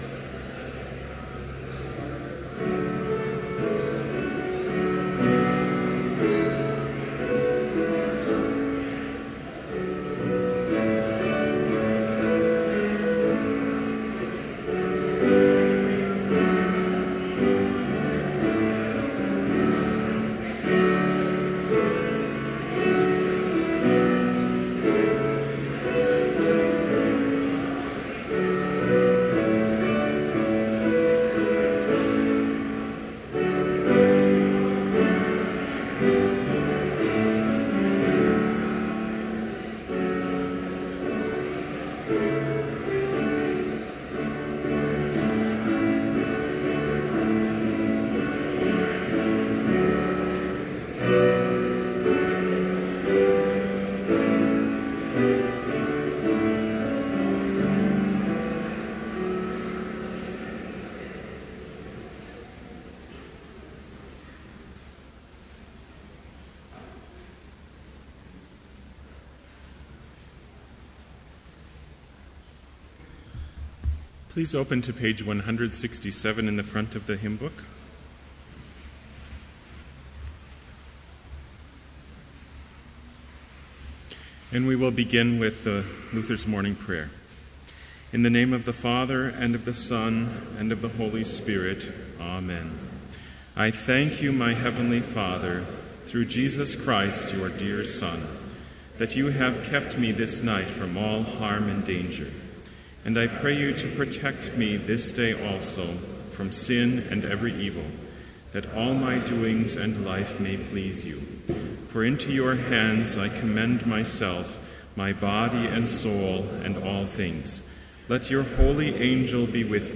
Complete service audio for Chapel - January 30, 2014